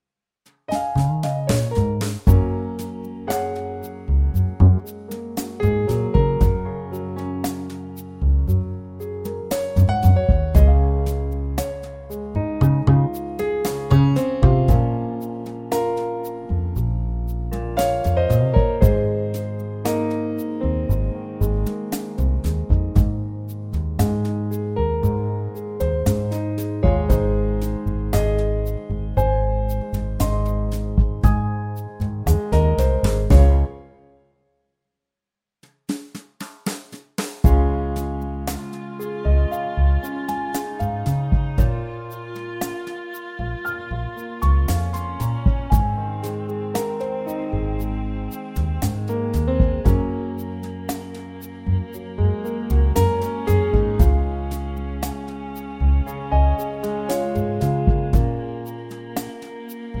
+3 Female Key